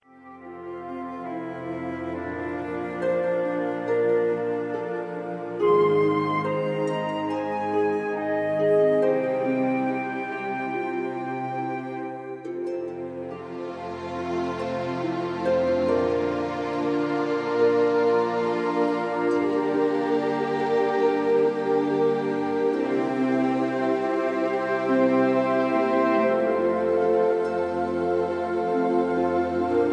(Key-F, Tono de F)
mp3 backing tracks